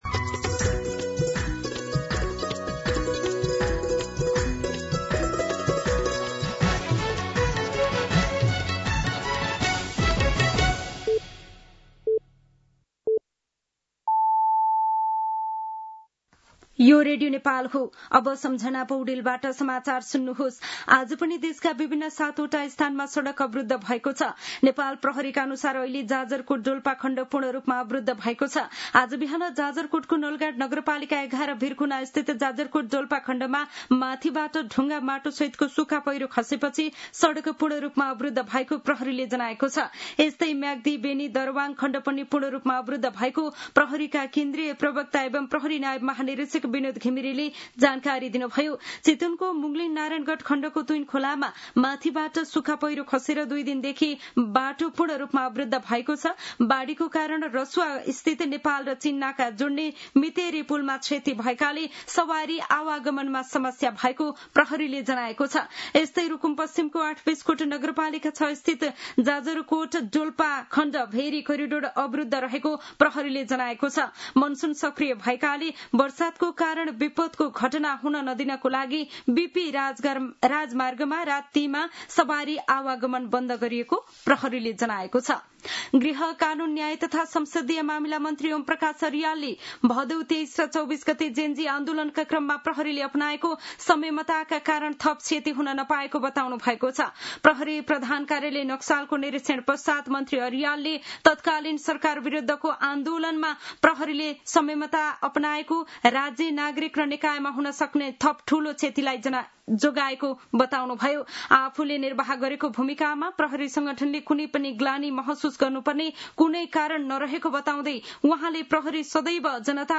दिउँसो १ बजेको नेपाली समाचार : ५ असोज , २०८२